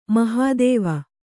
♪ mahādēva